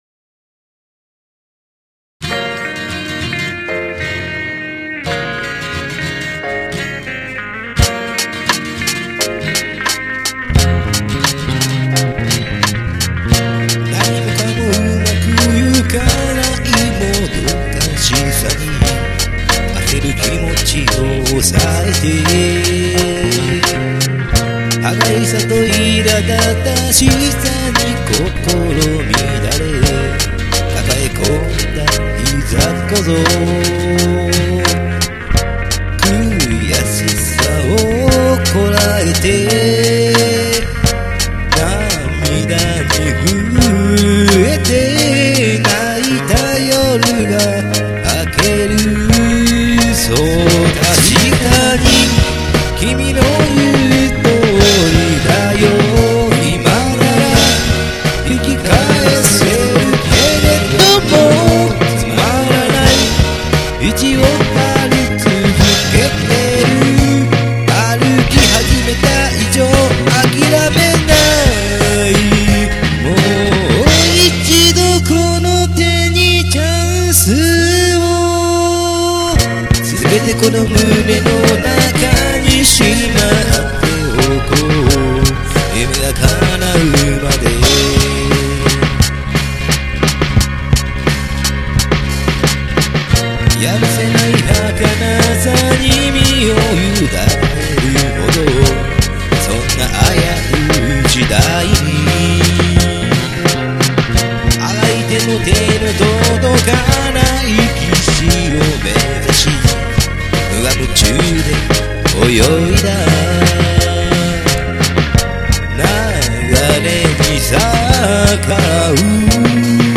AG  Gibson
ドラム  Pistoncollage
味付けは、デジタルディレイ少々、コンプレッションサスティナー長めで
イコライザーで補正して弾いちょります。
ソロパートは、それプラスRATで歪ませてんっすが、歪ませ過ぎて
ハウリング起こしております。